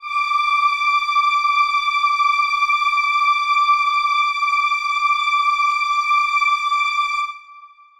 Choir Piano
D6.wav